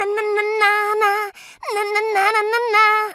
• Качество: 129, Stereo
голосовые
Веселая песенка